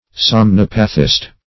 Somnipathist \Som*nip"a*thist\, n. A person in a state of somniapathy.